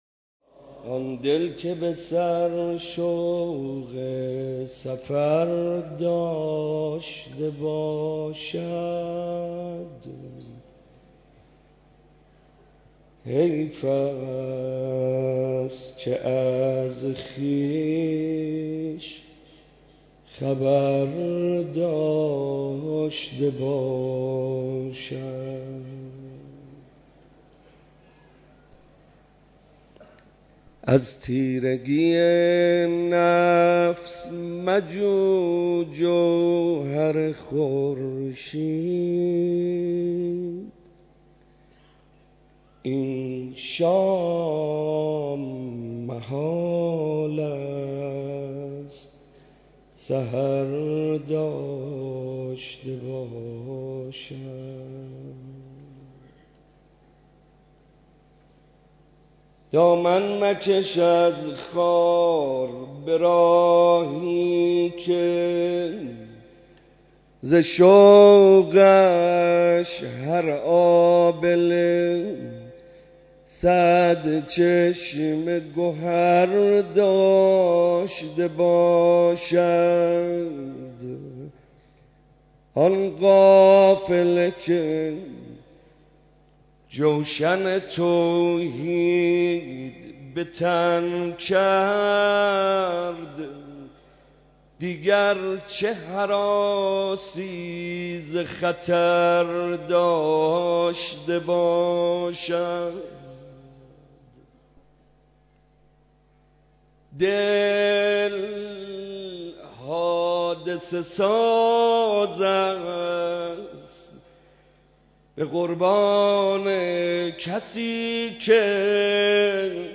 مراسم عزاداری شب تاسوعای حسینی
مراسم عزاداری شب تاسوعای حسینی علیه‌السلام برگزار شد